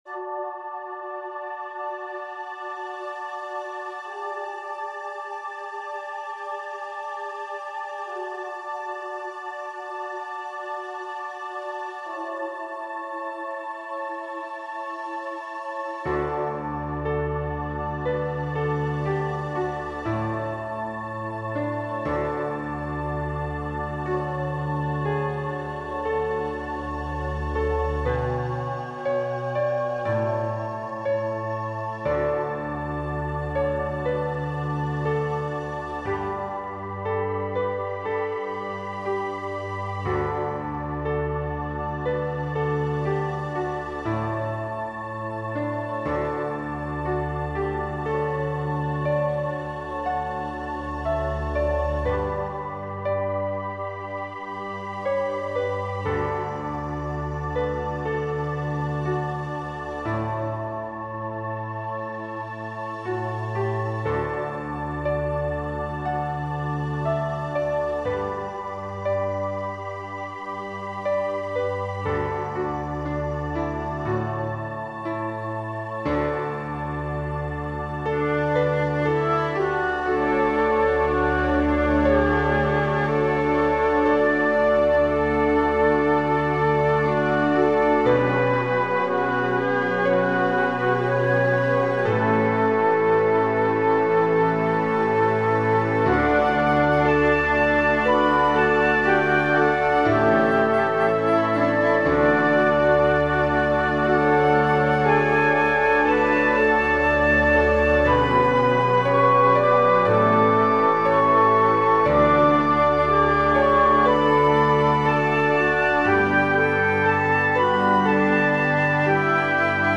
This is a Hymn arrangement written for my Great-Grandma's funeral. It is written in 159edo.